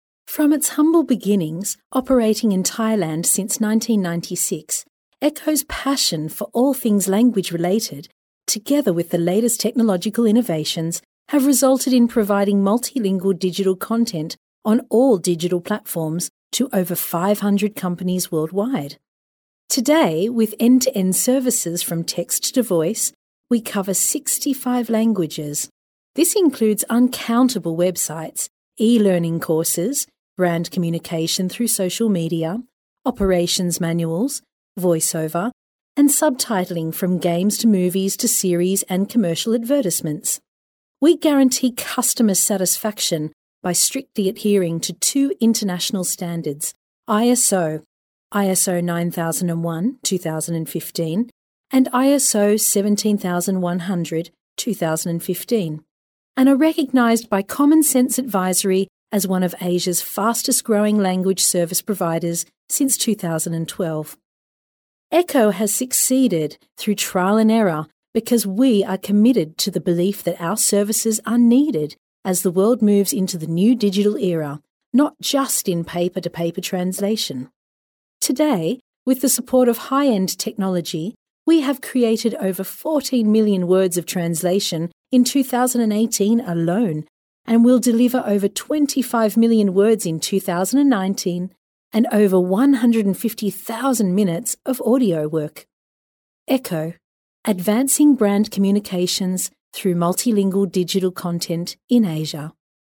EN (AUS) Female 03896
NARRATION